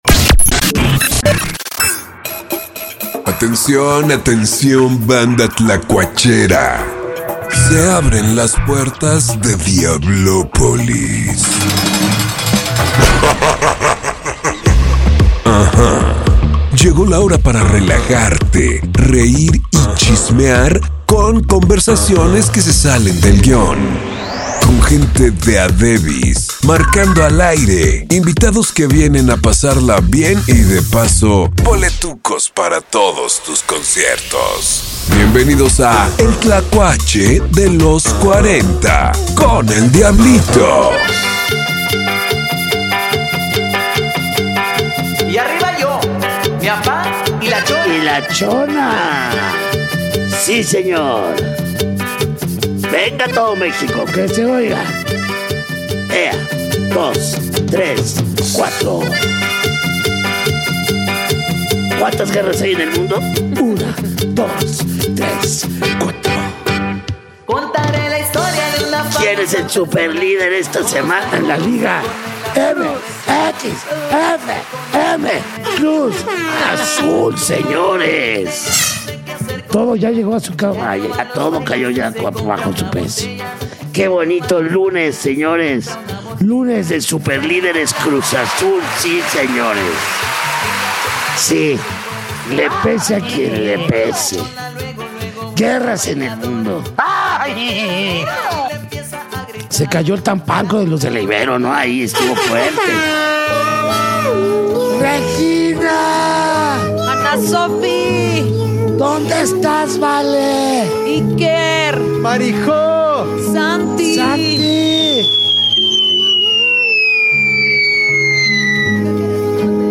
Merenglass en vivo desde Diablopolis…